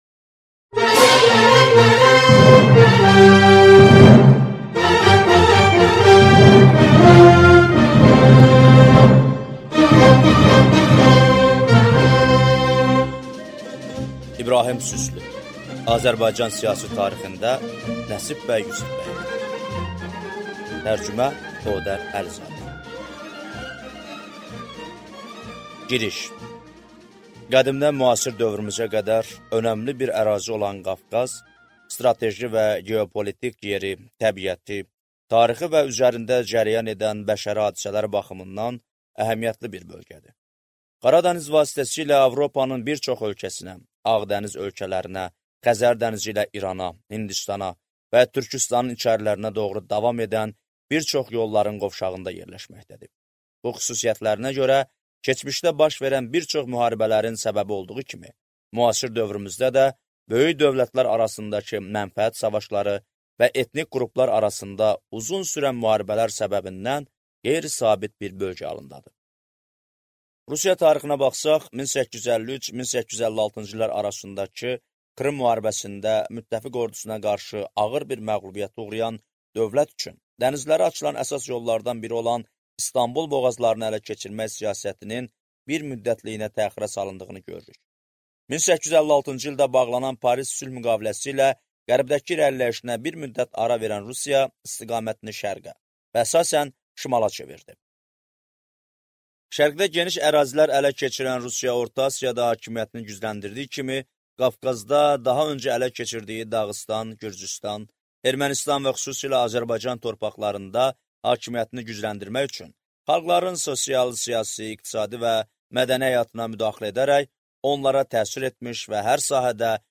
Аудиокнига Azərbaycan siyasi tarixində - Nəsib Bəy Yusifbəyli | Библиотека аудиокниг